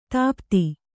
♪ tāpti